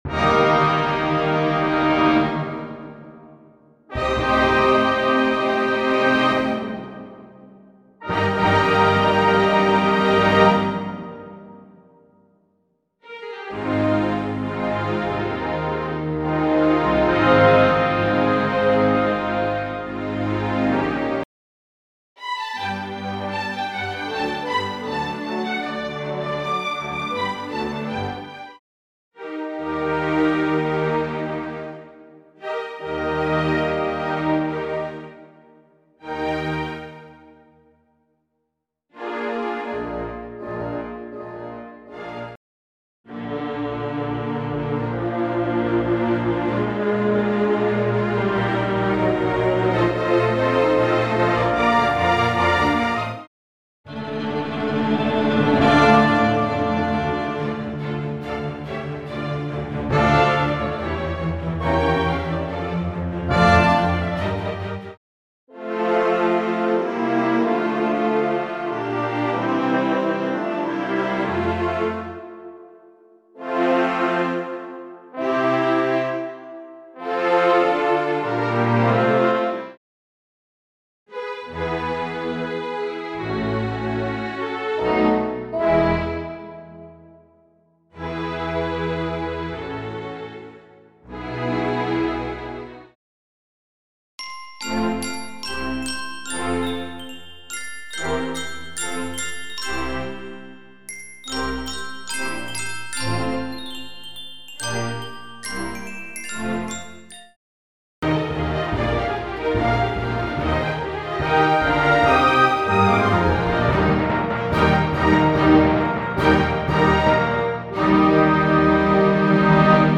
Full Orchestral Accompanmiment Karaoke tracks